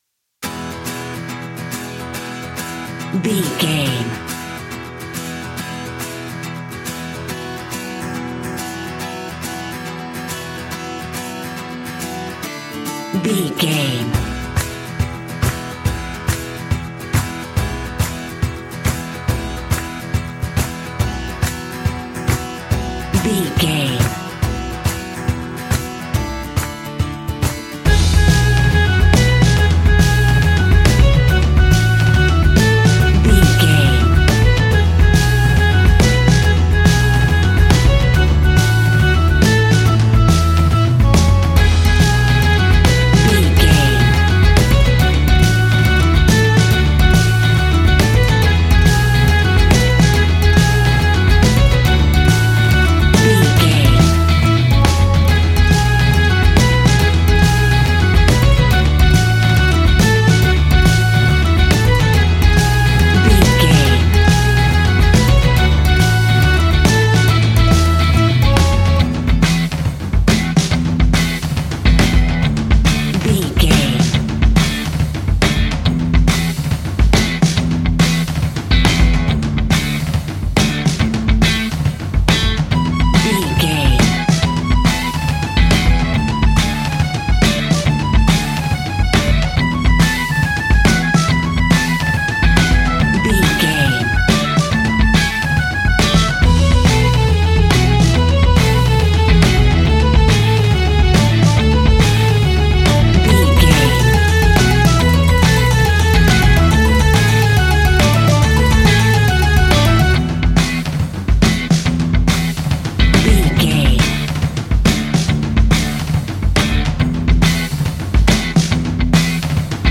Ionian/Major
instrumentals
acoustic guitar
mandolin
double bass
accordion